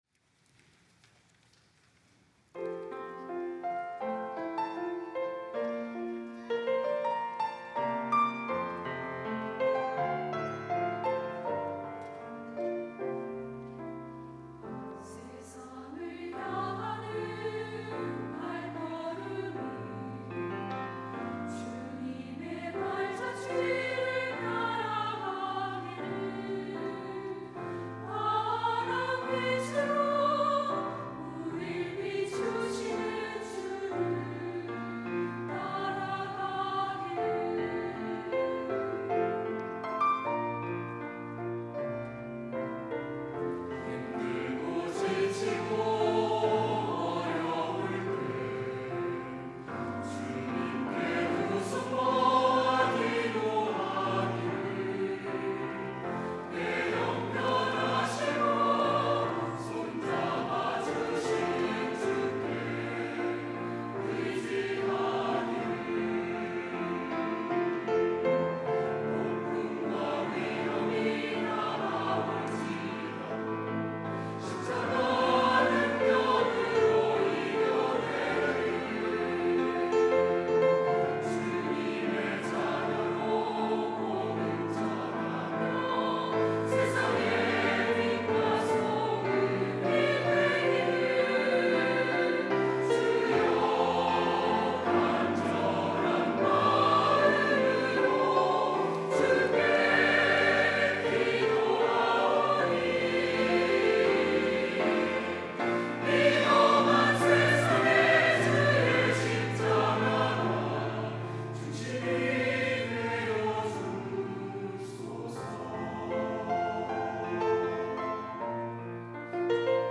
할렐루야(주일2부) - 내 간절한 소원
찬양대 할렐루야